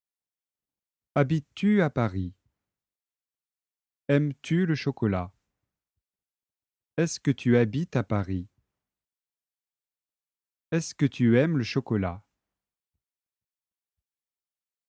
📌 L’intonation baisse en fin de phrase = ton neutre :